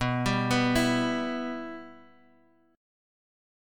Show Bsus4#5 results in Chord Calculator.